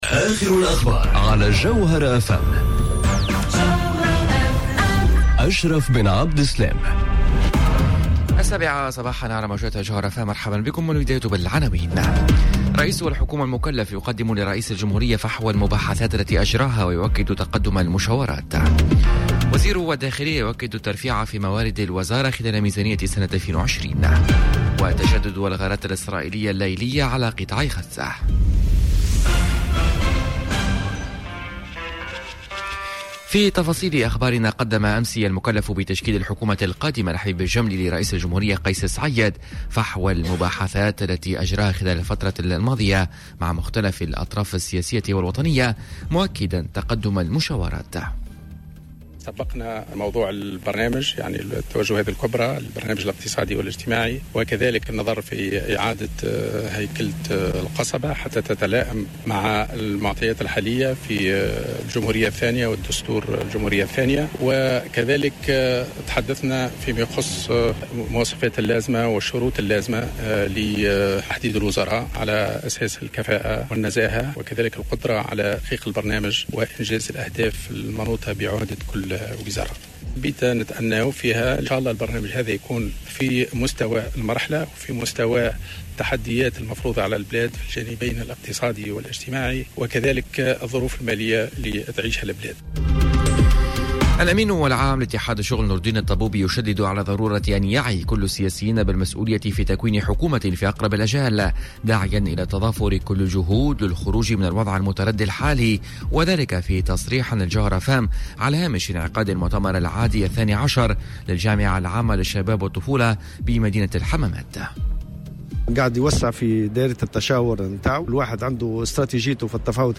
نشرة أخبار السابعة صباحا ليوم الإربعاء 27 نوفمبر 2019